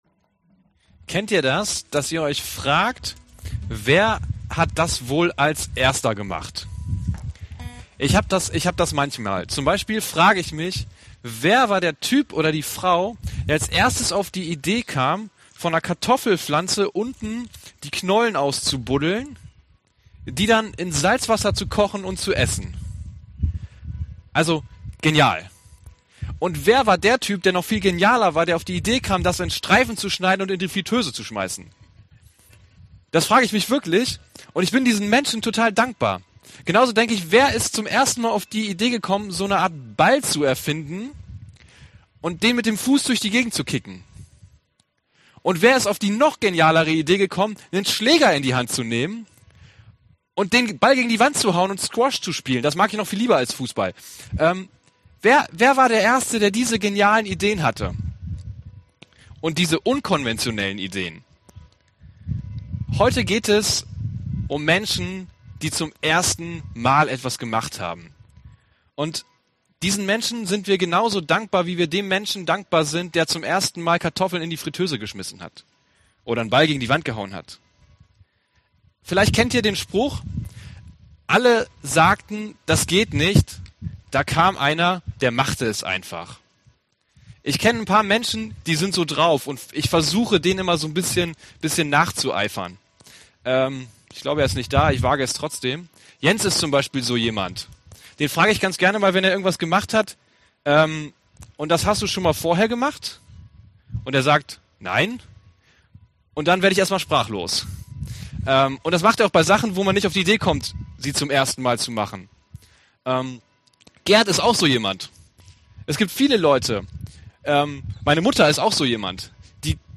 Hinfallen, Aufstehen, Weitermachen – FeG Oldenburg